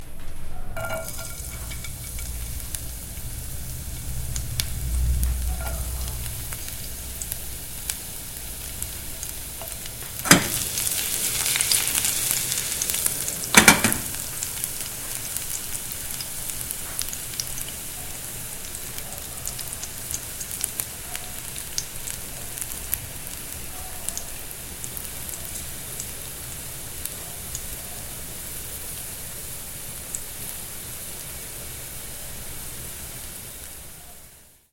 Звук жареного чеснока на раскаленной сковороде